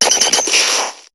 Cri de Taupiqueur dans Pokémon HOME.